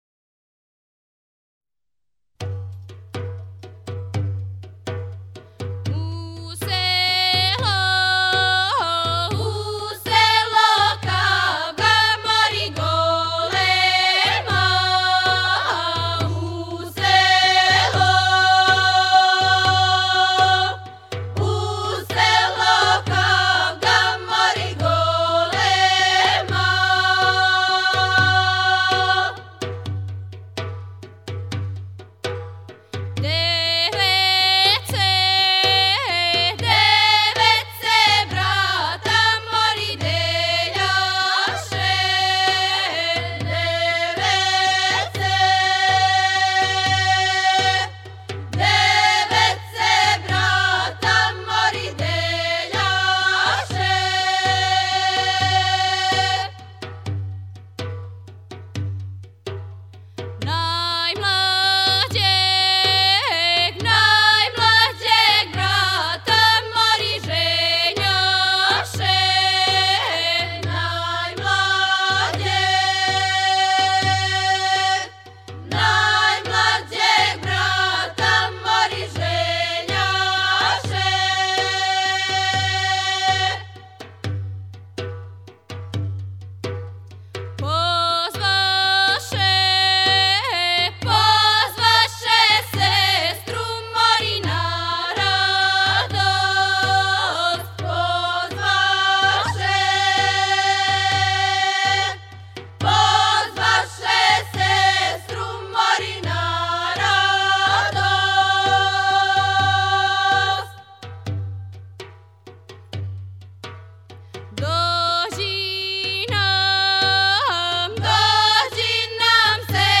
Женска група